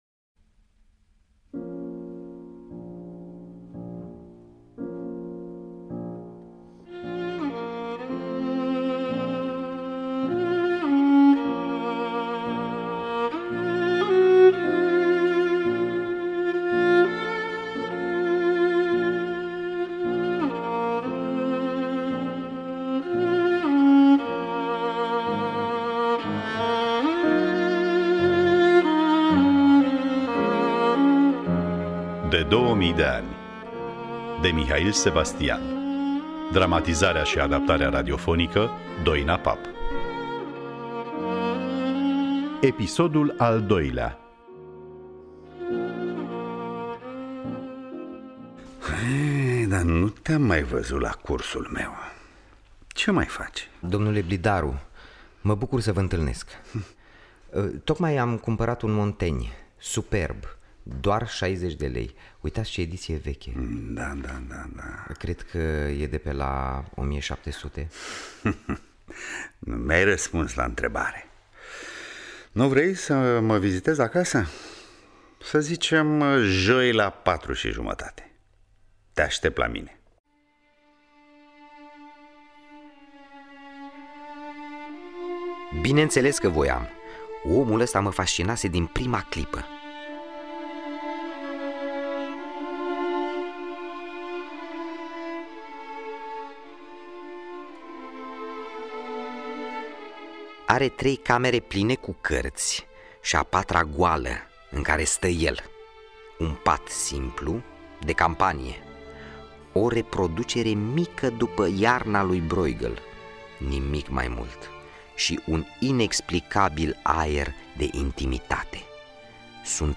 Dramatizarea şi adaptarea radiofonică